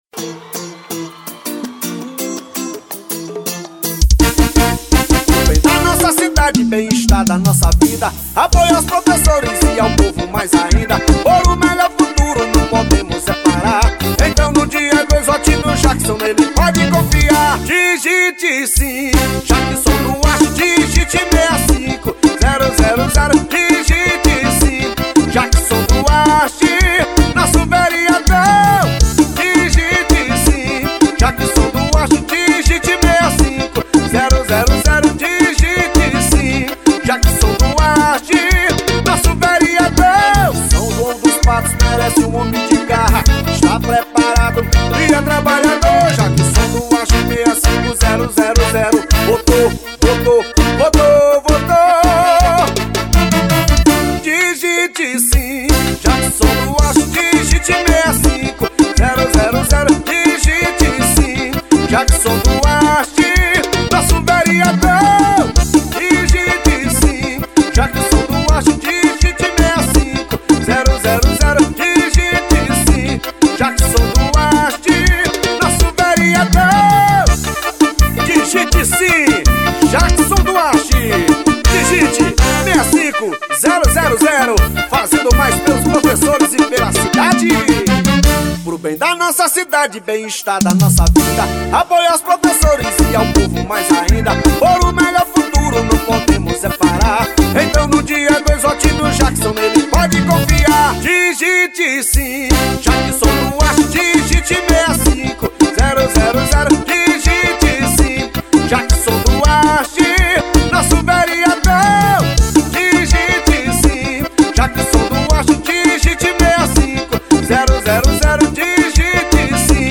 EstiloJingles / Spots